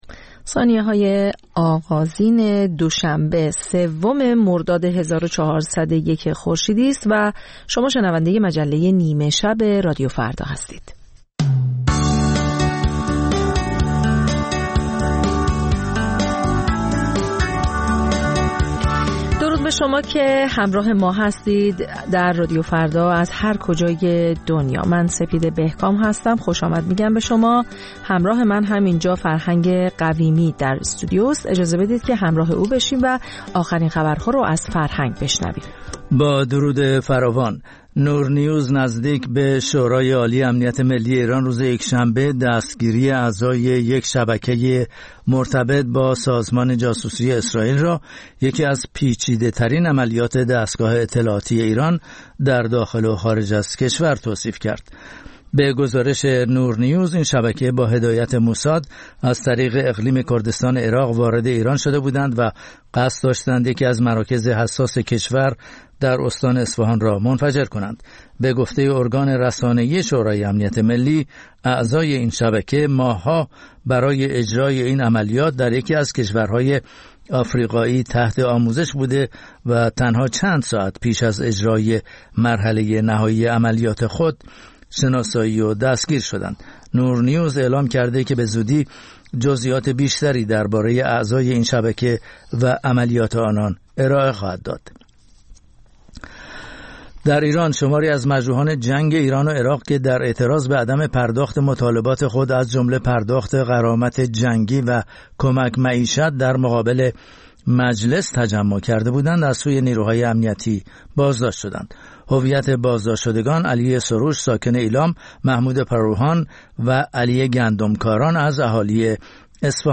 همچون هر روز، مجله نیمه شب رادیو فردا، تازه ترین خبر ها و مهم ترین گزارش ها را به گوش شما می رساند.